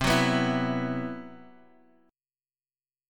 Esus2/C chord
E-Suspended 2nd-C-8,9,9,9,x,x.m4a